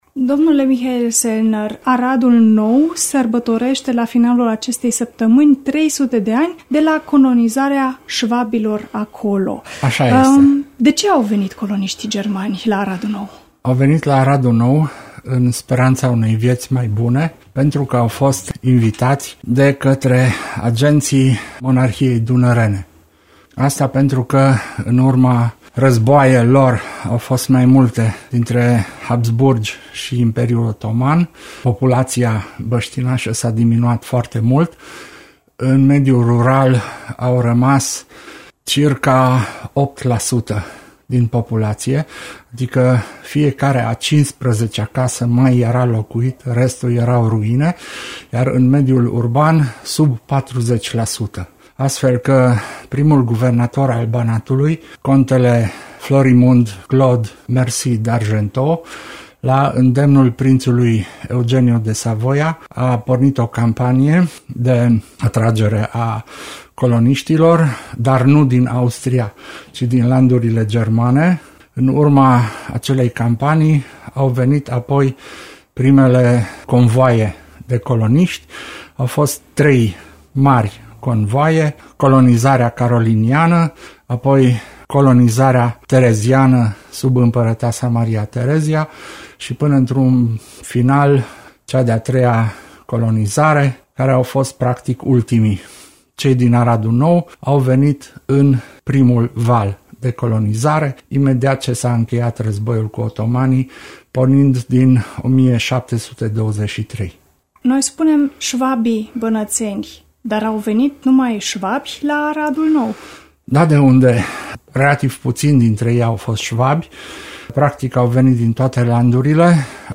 INTERVIU| Sărbători importante pentru comunitatea germanilor din Aradul Nou - Radio România Timișoara